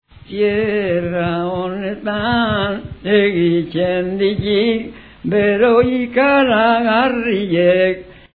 25-The automatic palatalization after -i is general and stronger than in other zones (it affects, for example, the auxiliary verb: ttuzu, ttugu etc). This does not happen in Etxalar, where there is no palatalization: